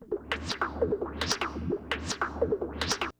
percussion.wav